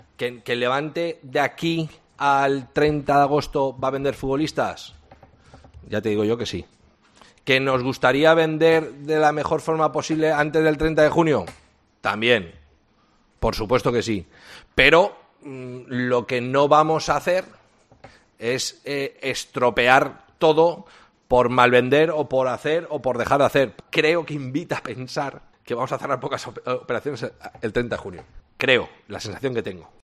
La rueda de prensa ha sido presencial por primera vez desde marzo de 2020